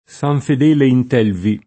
Fedele, Fedeli — latinismo ant. fidele [fid$le], possibile un tempo in occasionali usi poetici o letterari — fedele solo con -e- chiusa a Firenze (l’-e- lunga del lat. fidelis); invece con -e- aperta (cioè col trattam. delle voci dòtte) a Roma, in gran parte del Centro e nella Tosc. pisana e lucchese